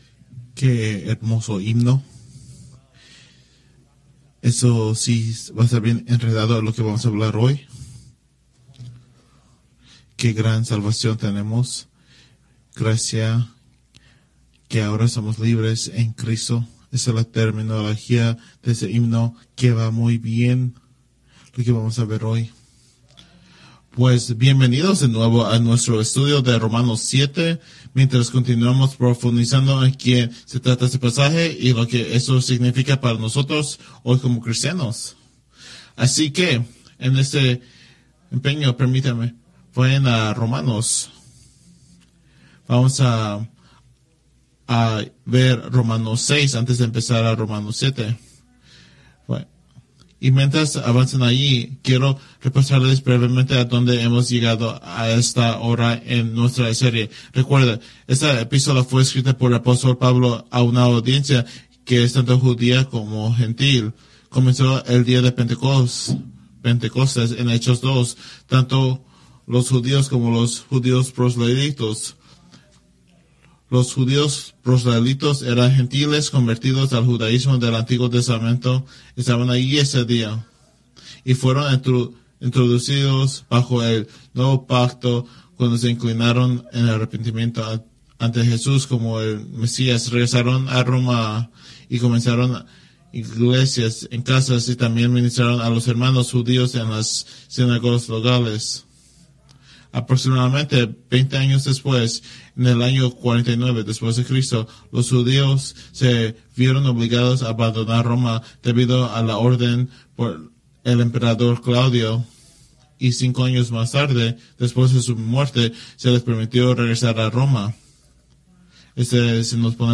Preached July 14, 2024 from Romans 7